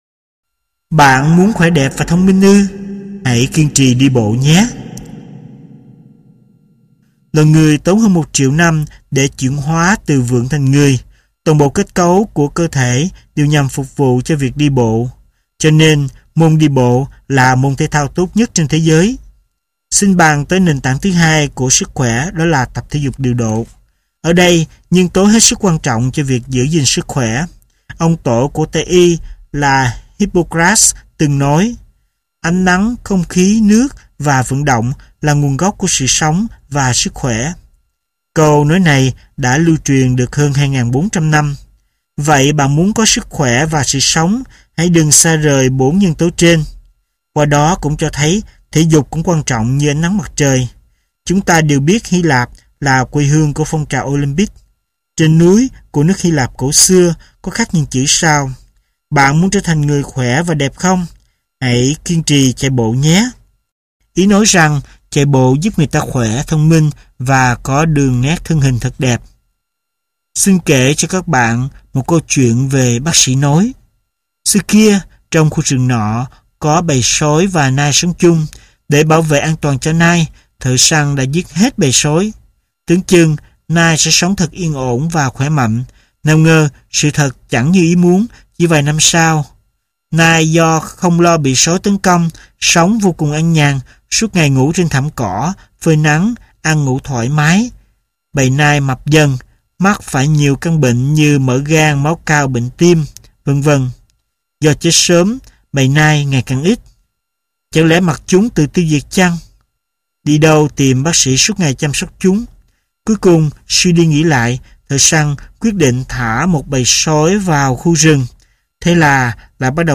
Sách nói Bác Sĩ Tốt Nhất Là Chính Mình tập 1 - Sách Nói Online Hay